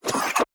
Robotic Next Page Buttton.wav